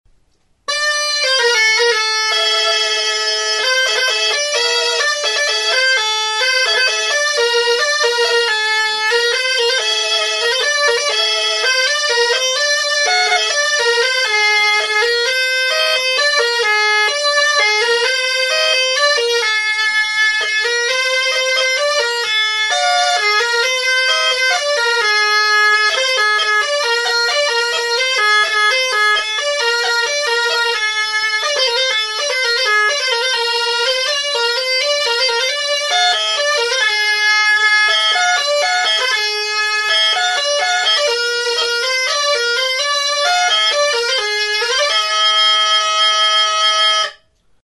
Aerophones -> Reeds -> Single fixed (clarinet)
Recorded with this music instrument.
Klarinete bikoitza da.